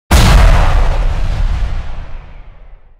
Boom Explosion